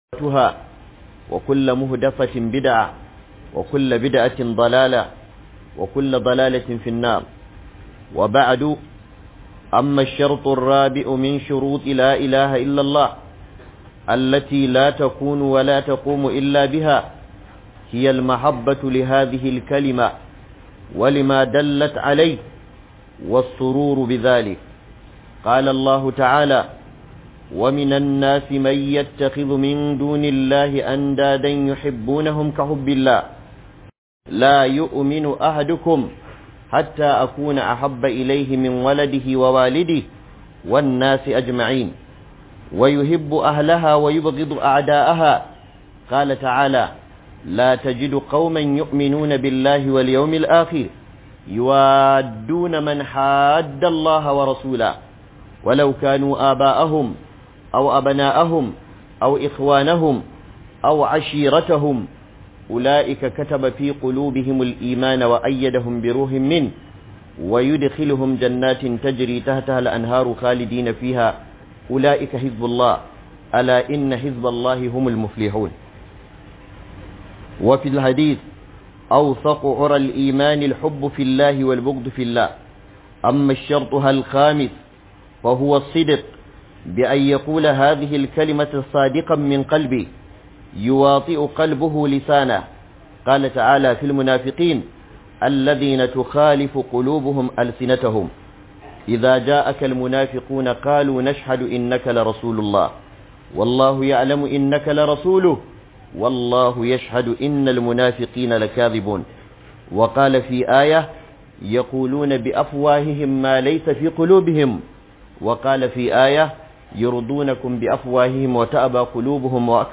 Sharuddan kalmar shahada - Huduba